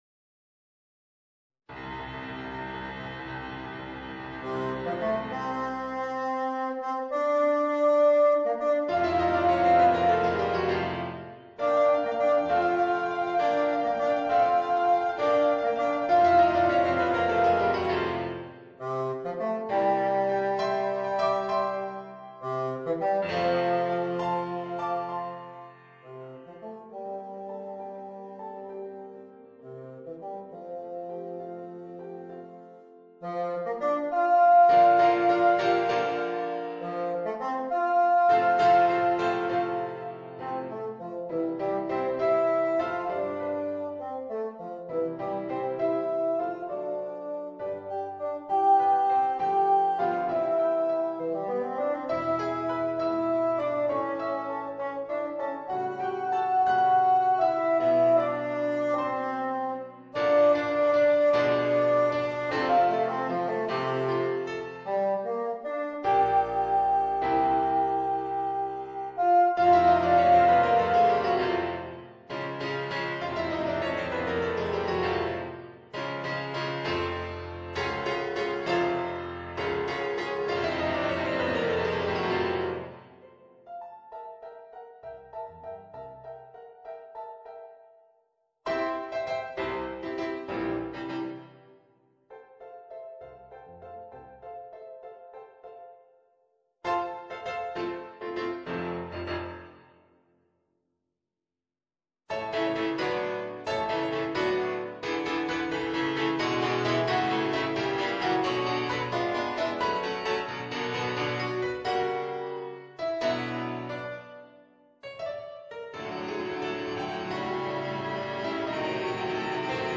Voicing: Bassoon and Piano